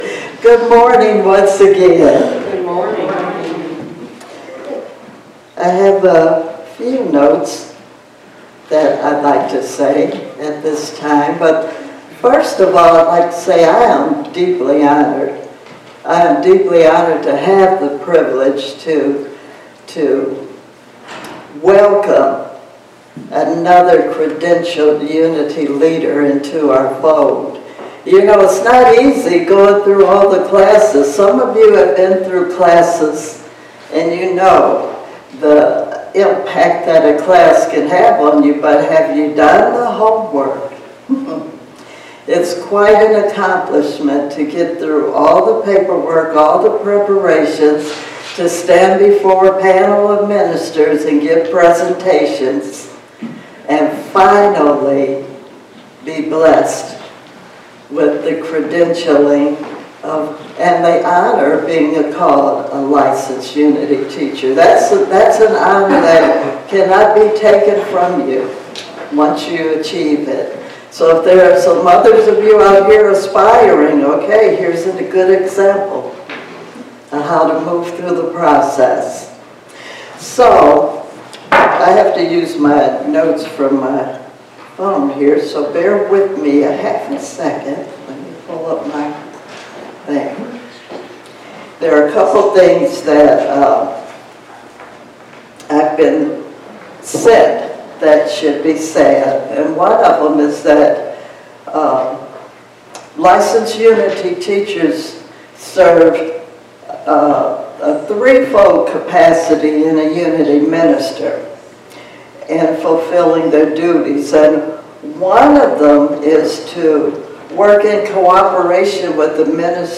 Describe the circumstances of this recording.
11-26-23 Sunday Service